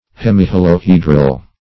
Search Result for " hemiholohedral" : The Collaborative International Dictionary of English v.0.48: Hemiholohedral \Hem`i*hol`o*he"dral\, a. [Hemi- + holohedral.]